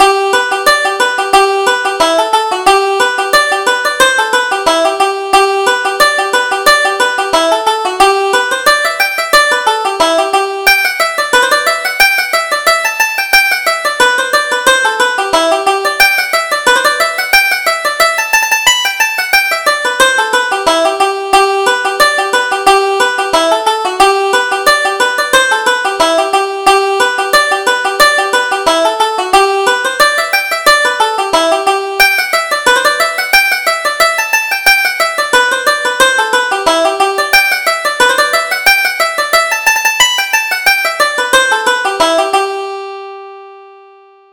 Reel: The Drummond Lasses